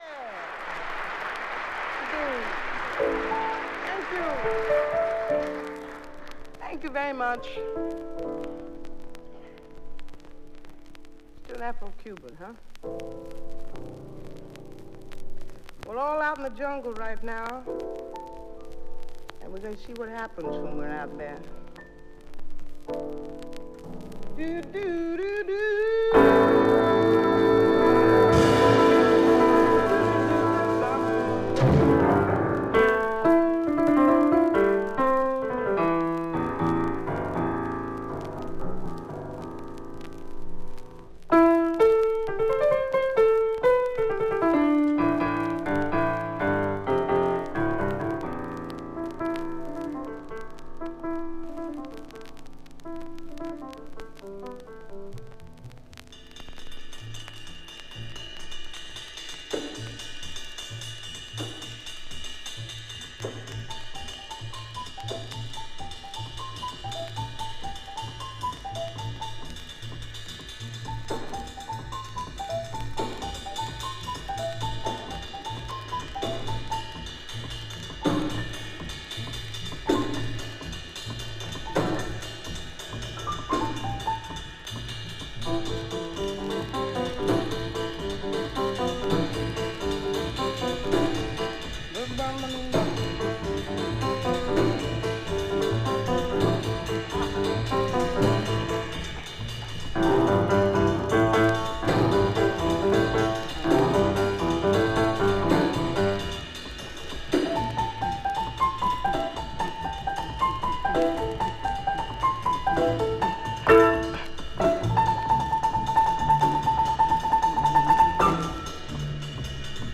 瑞々しさと魂の歌。スタンダード「Summertime」はインストとヴォーカルと奏でられている。